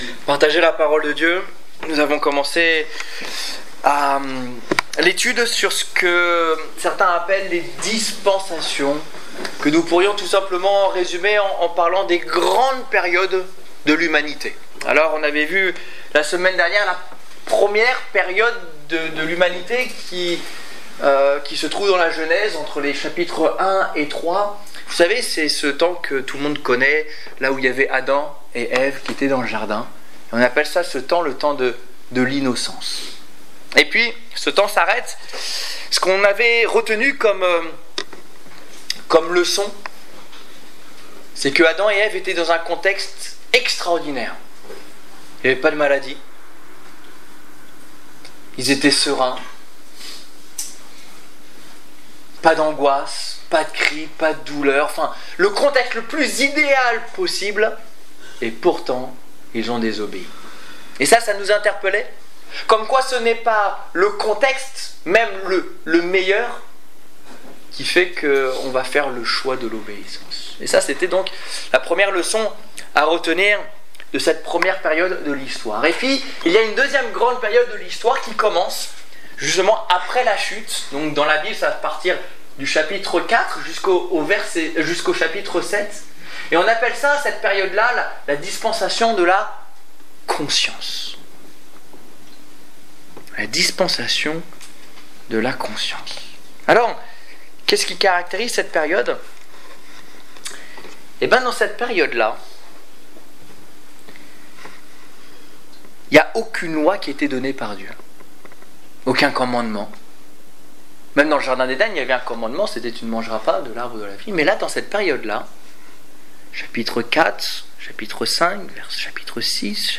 Étude biblique du 25 février 2015